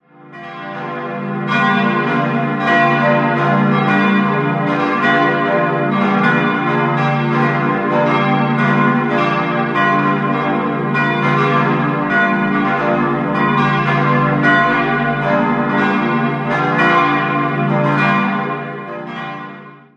In den Jahren 1729 bis 1731 wurde die Stadtpfarrkirche zum Heiligen Georg nach den Plänen von Franz Joseph Roth errichtet und erhielt eine reiche, barocke Ausstattung. 4-stimmiges Geläut: h°-d'-f'-g' Die Glocken wurden im Jahr 1922 vom Bochumer Verein für Gussstahlfabrikation gegossen.